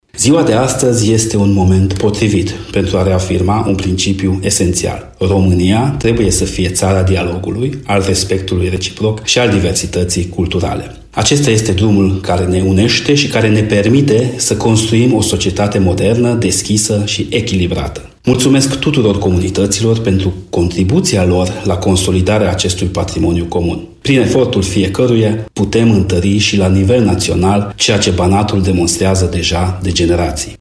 Deputatul UDMR în Parlamentul României, Molnar Andras, susține că acest model de conviețuire poate fi extins în toate regiunile țării și reafirmă importanța respectului și cooperării între comunități:
Molnar-Andras.mp3